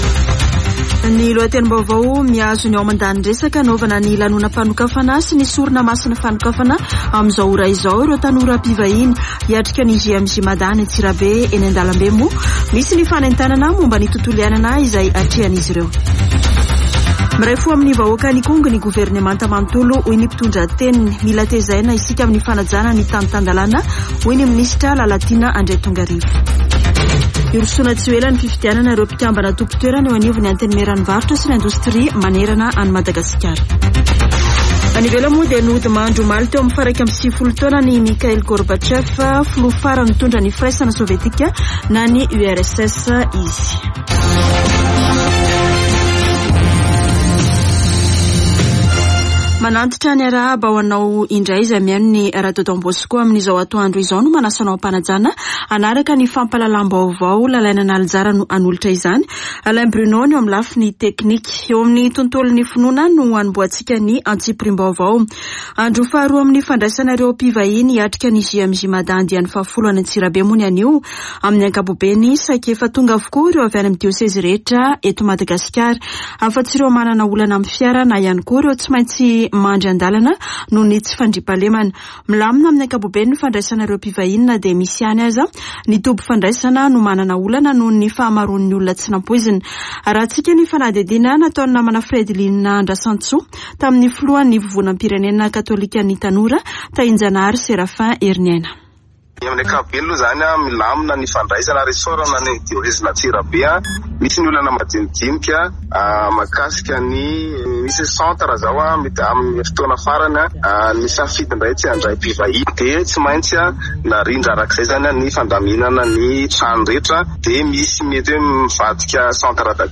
[Vaovao antoandro] Alarobia 31 aogositra 2022